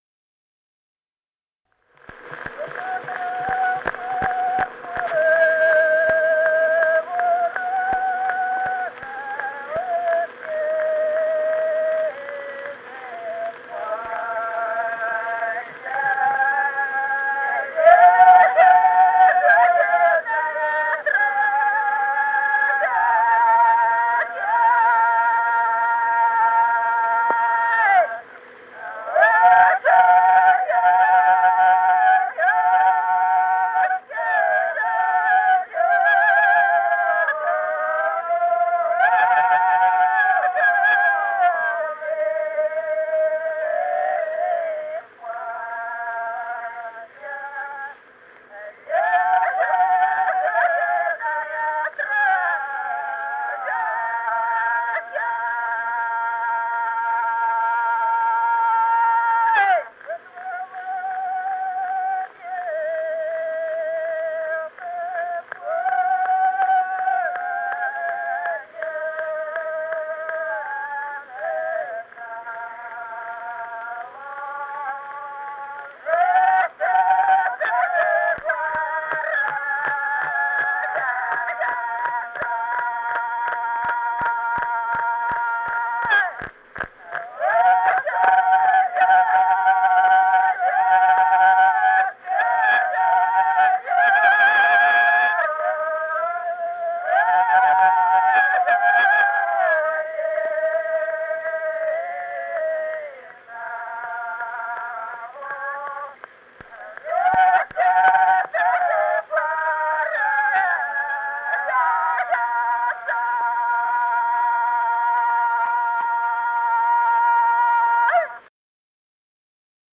с. Остроглядово.